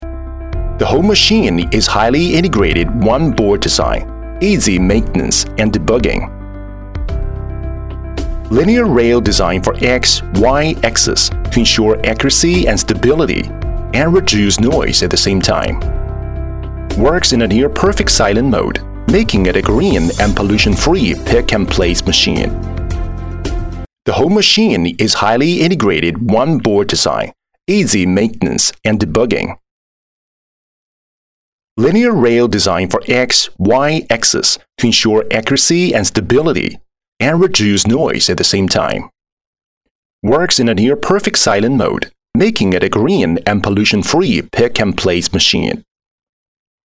YY1(产品介绍)